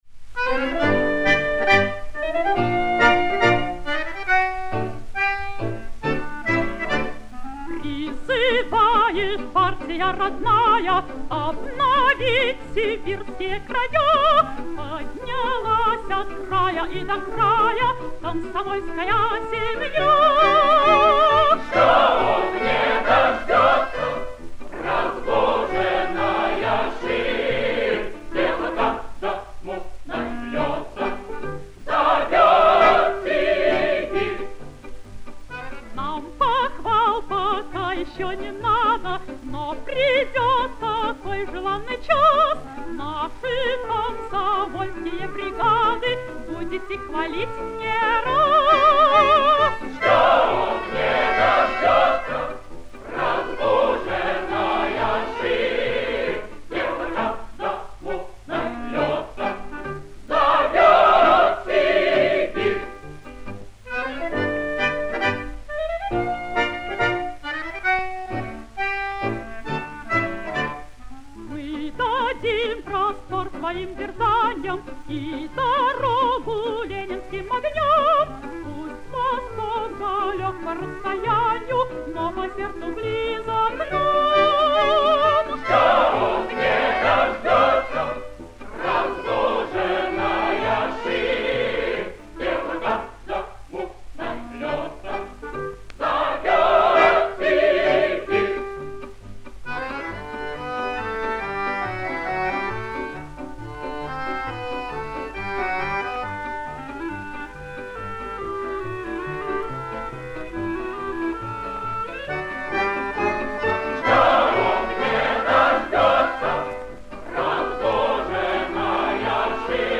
Улучшение качества.